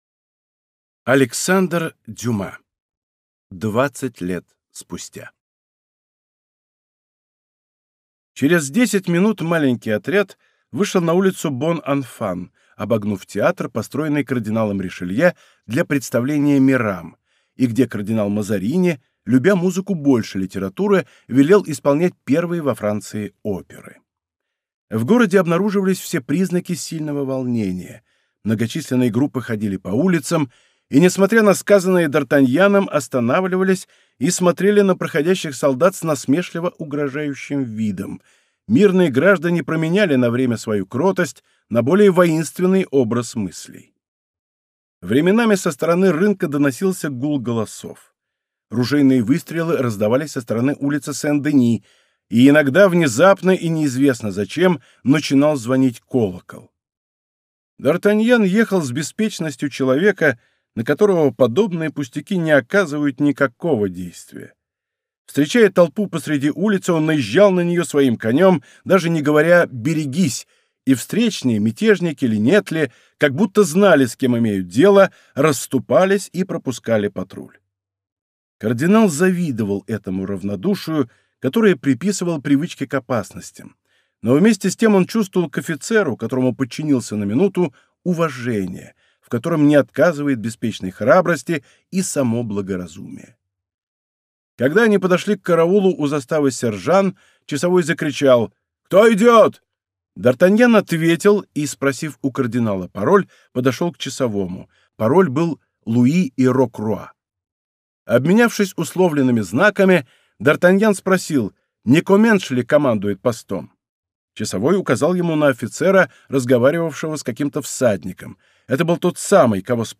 Аудиокнига Двадцать лет спустя | Библиотека аудиокниг